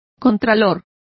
Complete with pronunciation of the translation of comptrollers.